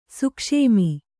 ♪ sukṣēmi